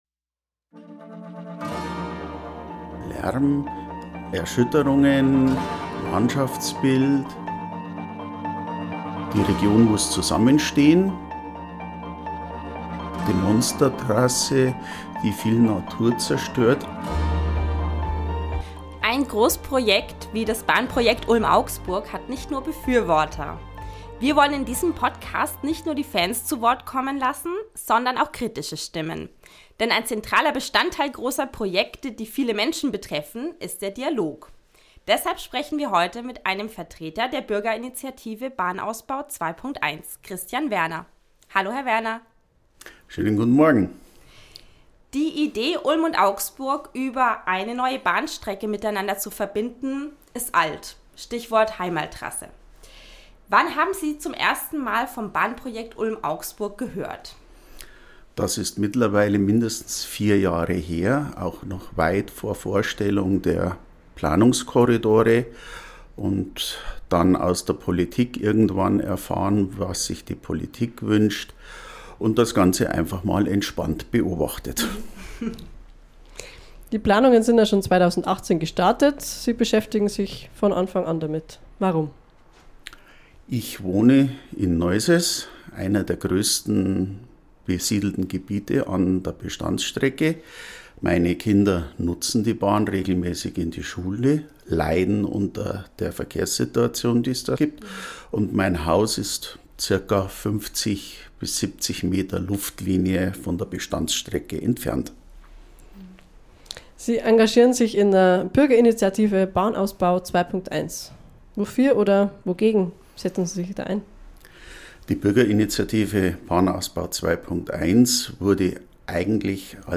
Ein Gespräch darüber, was Zusammenhalt in der Region bedeutet, wofür und wogegen er sich einsetzt und was die Bürgerinnen und Bürger in Neusäß wirklich möchten.